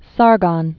(särgŏn) Died 705 BC.